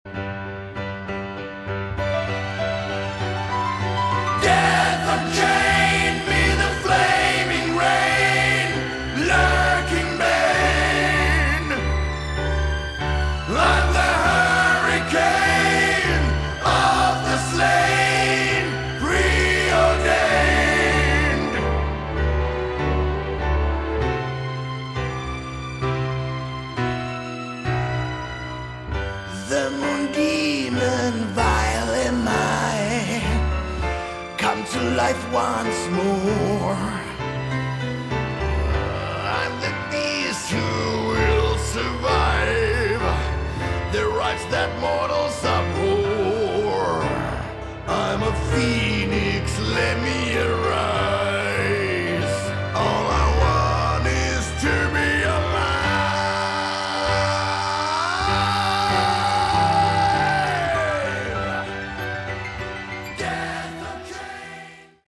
Category: Shock Rock/Horror Rock